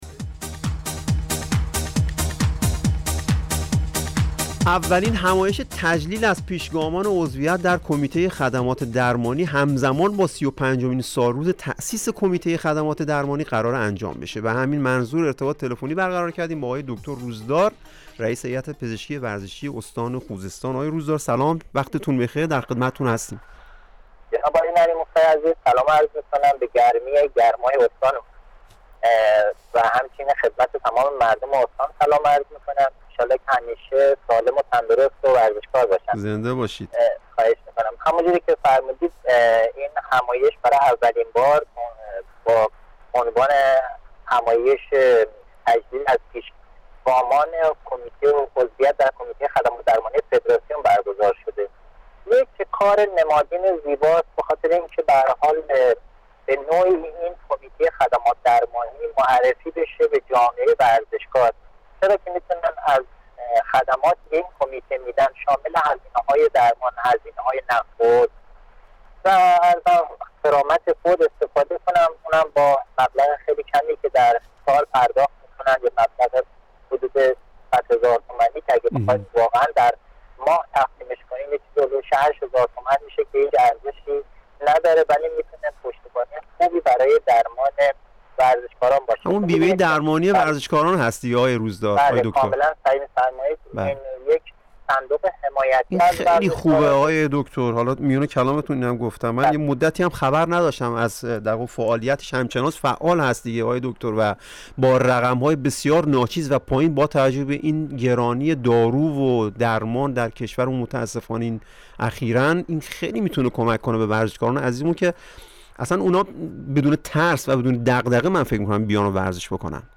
/ گفتگویی رادیویی /